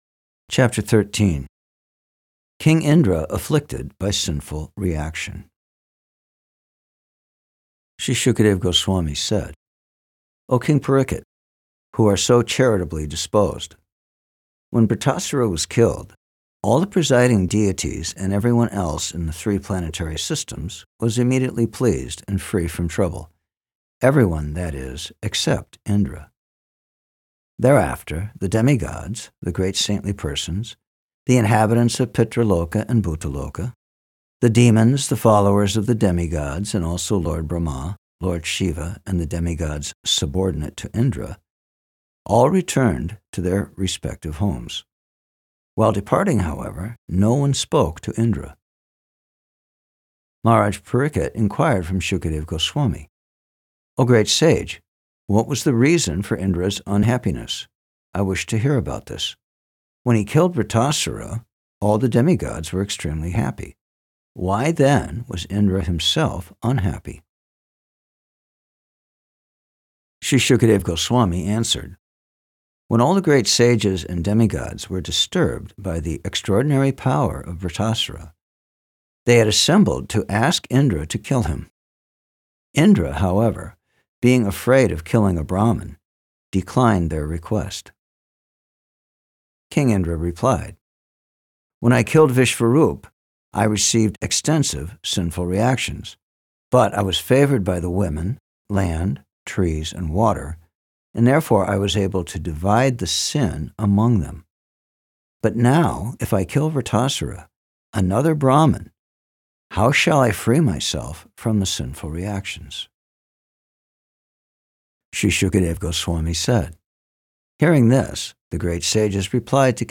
The best audio book I’ve ever listened, the voice is clear and pleasing thanks for not adding any background music.
13-Ch-13-SB-6th-Canto-Verses-Only.mp3